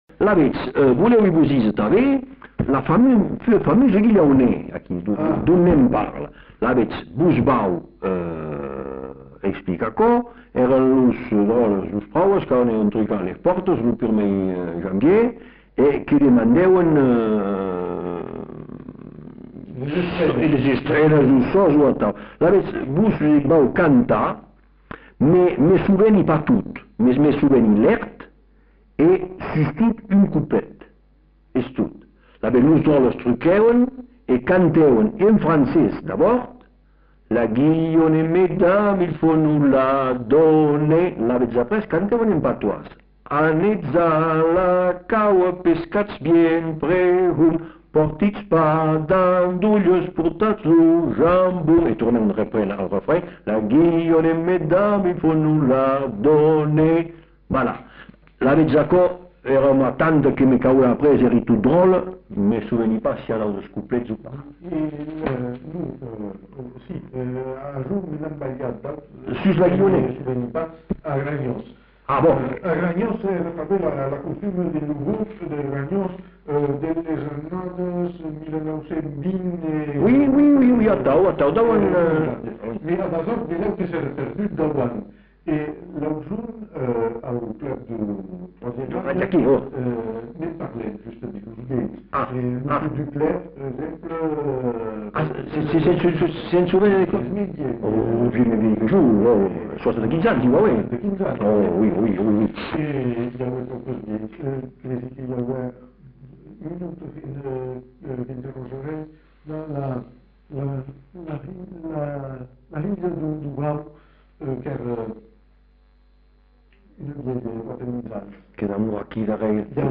Lieu : Bazas
Genre : chant
Effectif : 1
Type de voix : voix d'homme
Production du son : chanté
Classification : quêtes-Rois, Pâques, guillanneus et divers